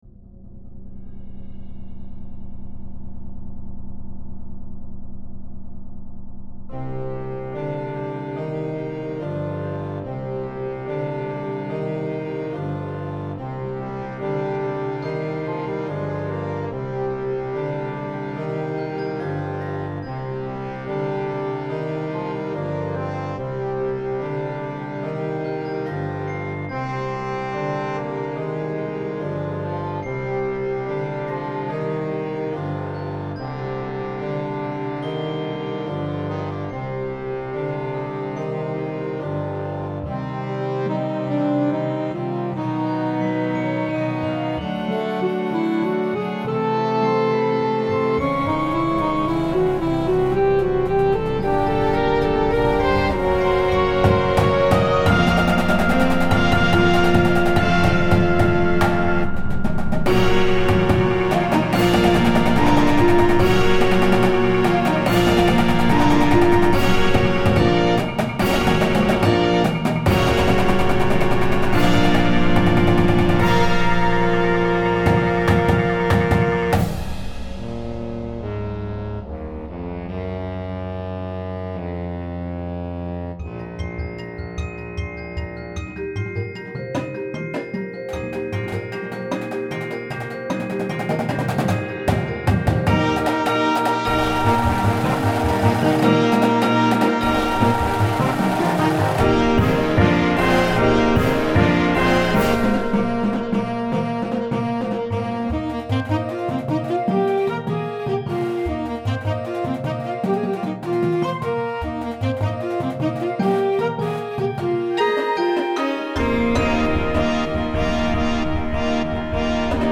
Winds and Percussion